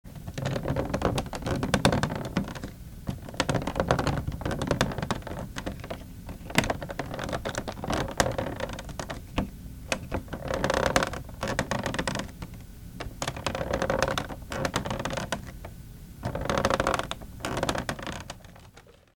Well, I mean, other than the creaking plastic, cosmetic imperfections on the surface of the case, flashing around key caps, case flex, extruded / protruded plastic on the back of the case:
Audio of creaking - the result of pressing both halves of the case together
Creaking.mp3